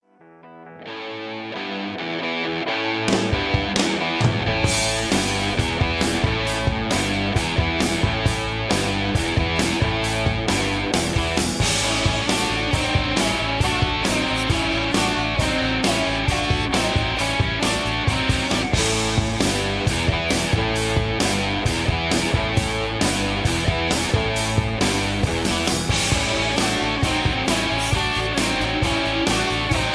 Tags: backingtracks , soundtracks , rock and roll